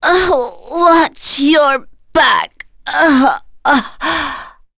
Epis-Vox_Dead.wav